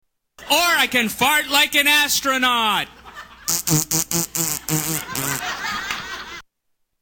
Fart 4